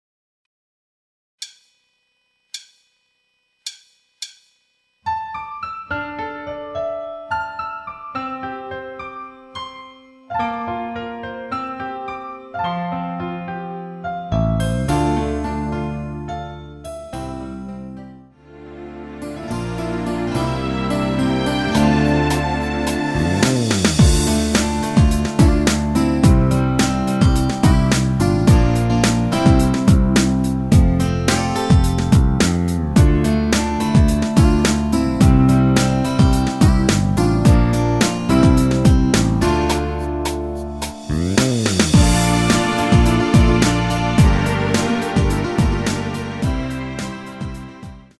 MR입니다.
원곡의 보컬 목소리를 MR에 약하게 넣어서 제작한 MR이며